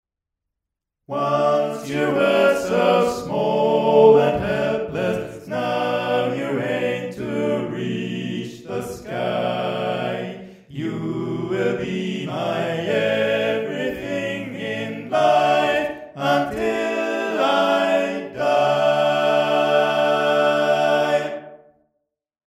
Key written in: D Major
Type: Barbershop